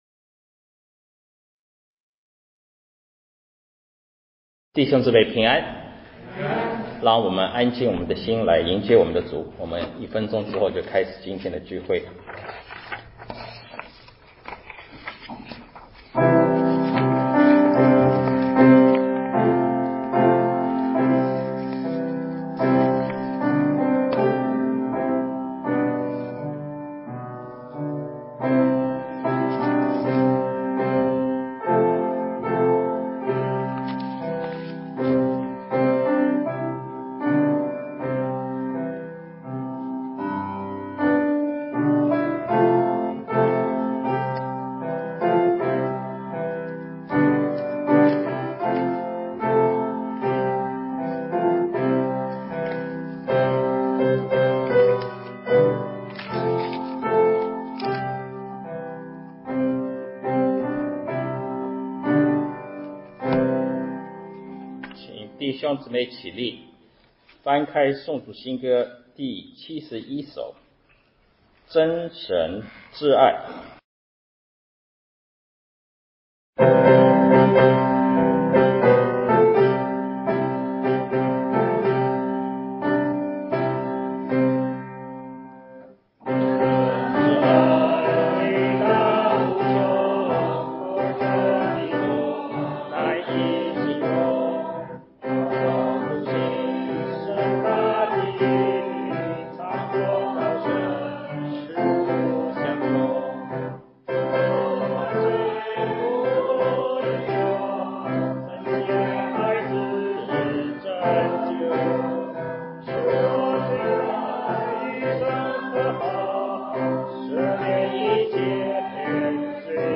11-07-21主日敬拜——主的服事-CD.mp3